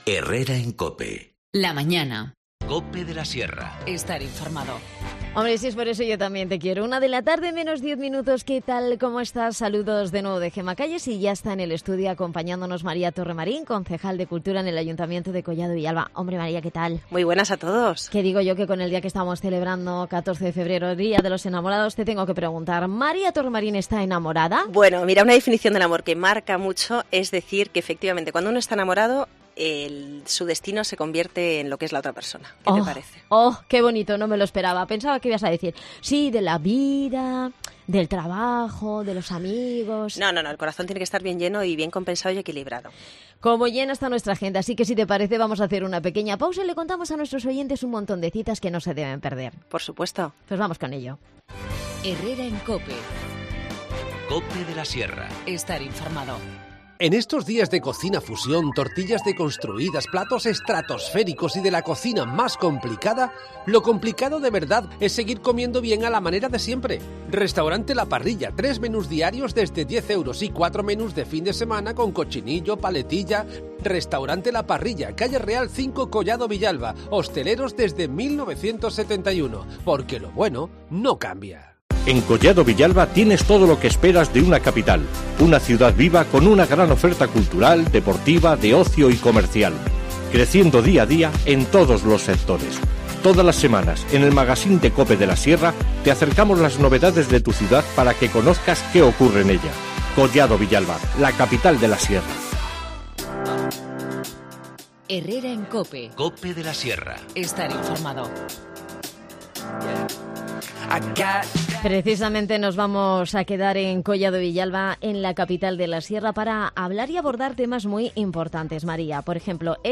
Redacción digital Madrid - Publicado el 14 feb 2019, 13:52 - Actualizado 15 mar 2023, 22:58 1 min lectura Descargar Facebook Twitter Whatsapp Telegram Enviar por email Copiar enlace María Torremarín, concejal de Cultura en Collado Villalba, repasa toda la actualidad del municipio y las citas más importantes para los próximos días.